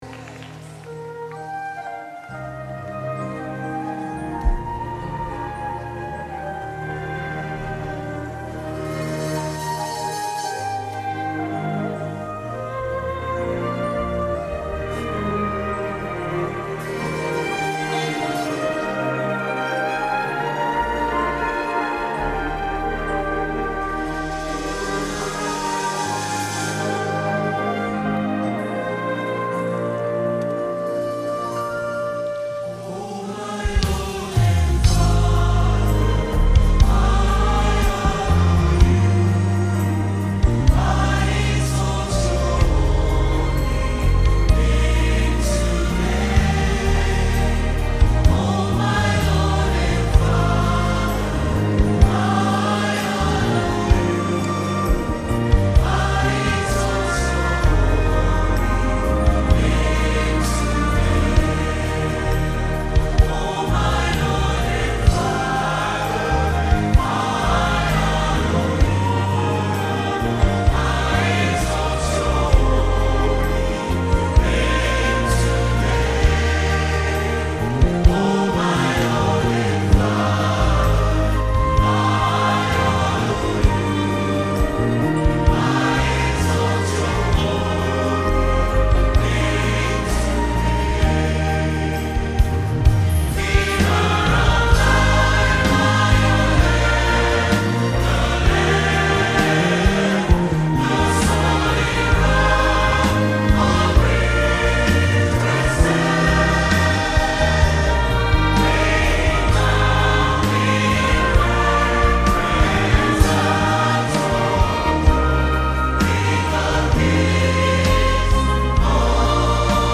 (Orchestra)